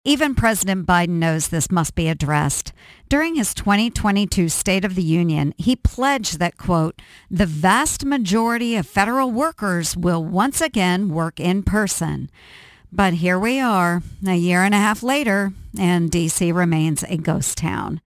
She commented briefly Wednesday during a conference call with Iowa reporters.